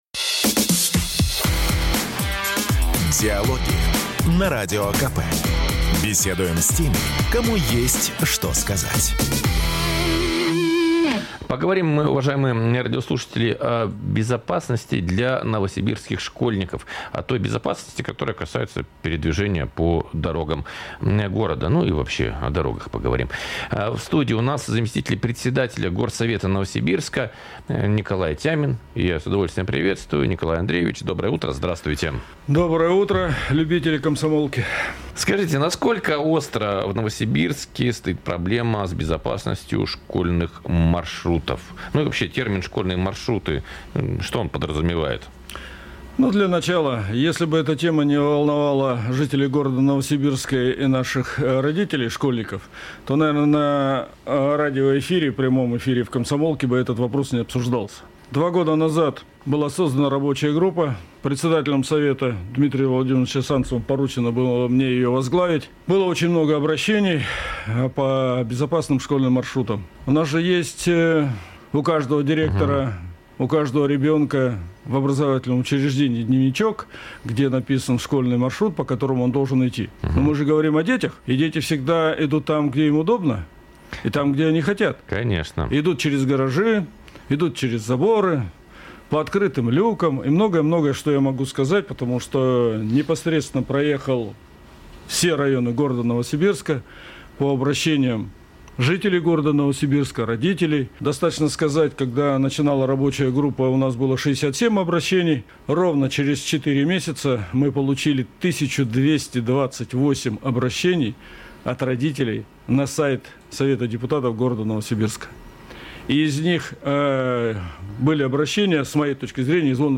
Запись программы, транслированной радио "Комсомольская правда" 18 августа 2025 года Дата: 18.08.2025 Источник информации: радио "Комсомольская правда" Упомянутые депутаты: Тямин Николай Андреевич Аудио: Загрузить